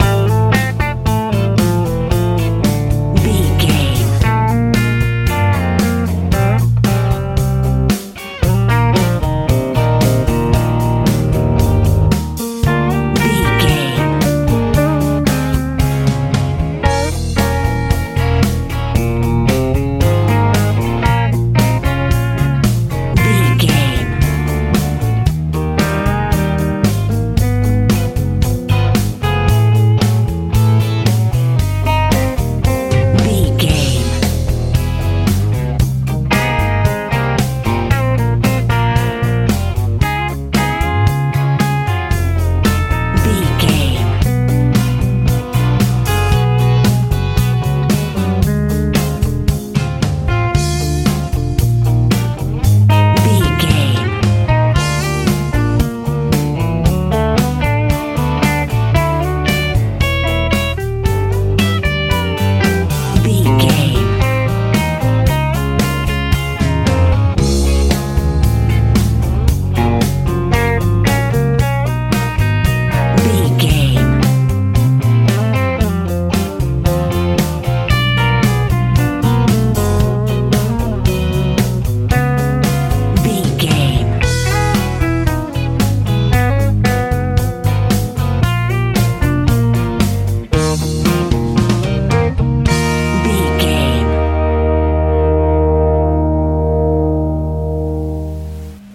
country rock feel
Ionian/Major
A♭
cool
wholesome
electric guitar
bass guitar
drums